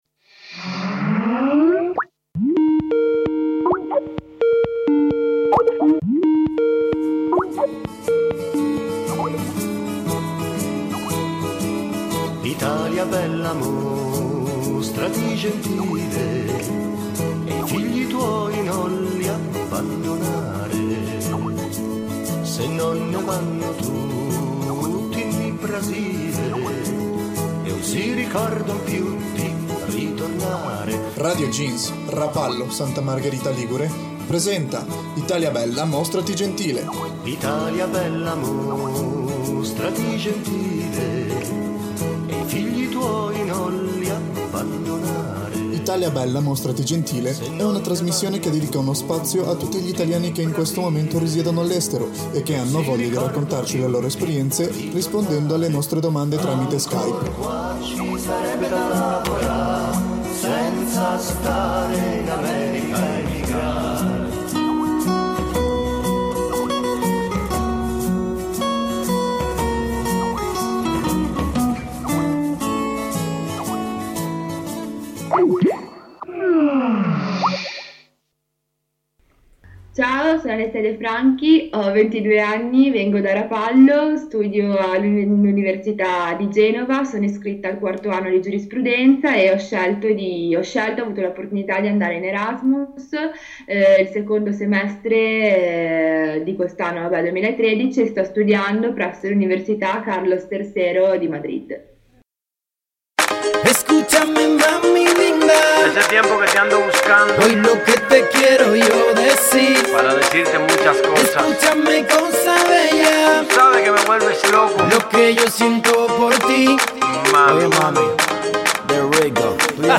Tutte le puntate vengono realizzate attraverso l'uso di Skype e quindi possiamo contattare le persone in tutto il mondo e anche, attraverso la web-cam, vedere queste persone durante le interviste.